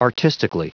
Prononciation du mot : artistically
artistically.wav